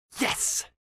Вы найдете разные варианты: от радостного и энергичного до томного и шепотного «yes», записанные мужскими, женскими и детскими голосами.